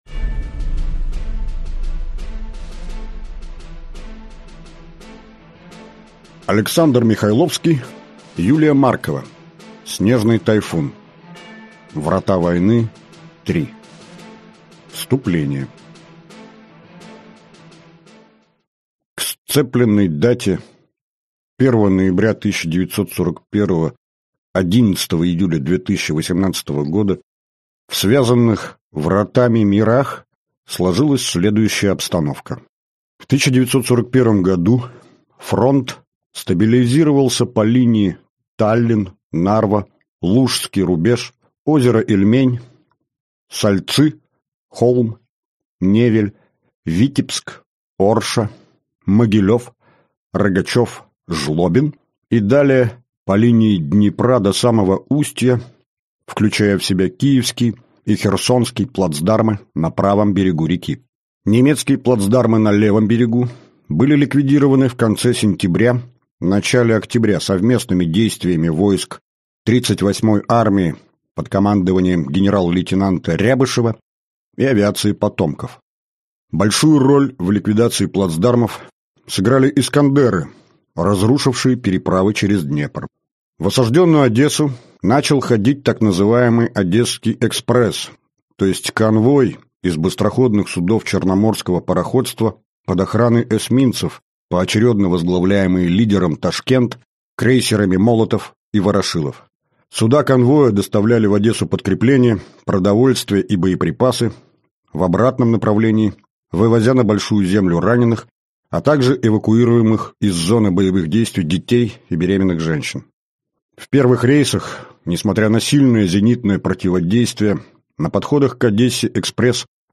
Аудиокнига Снежный Тайфун | Библиотека аудиокниг